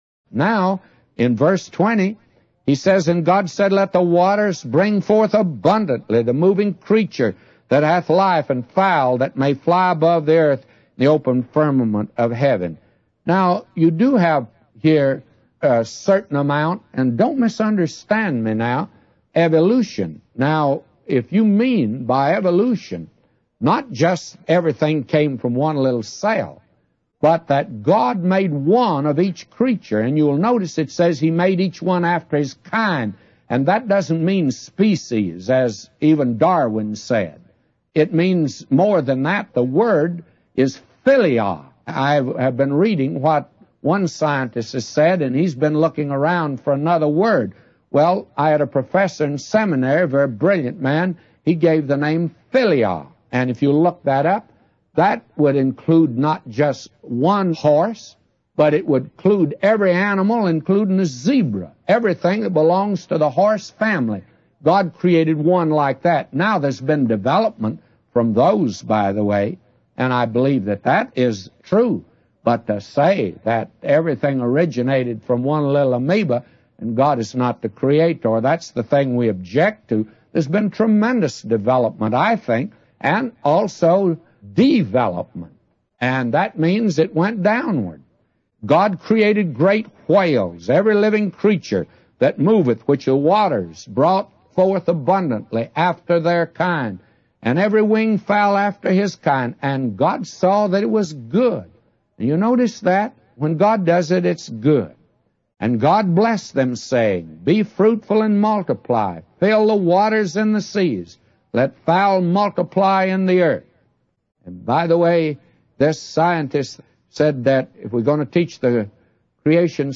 In this sermon, the preacher discusses the creation story as described in the Bible.